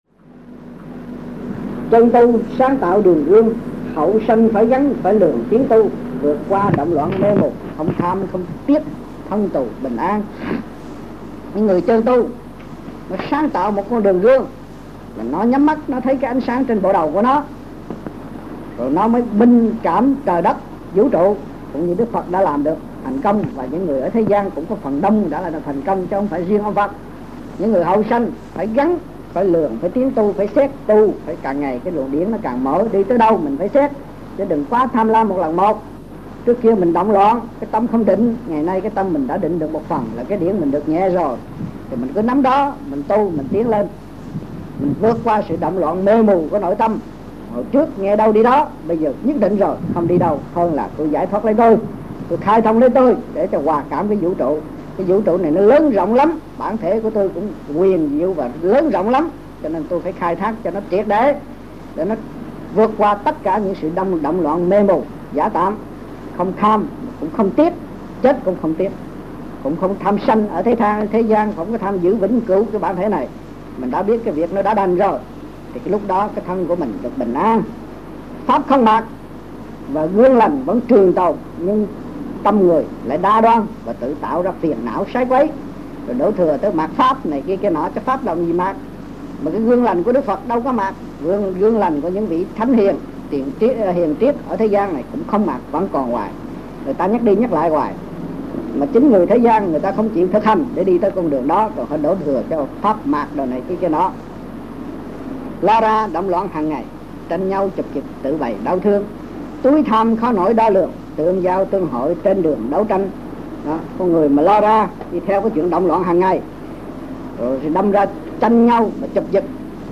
Trong dịp : Sinh hoạt thiền đường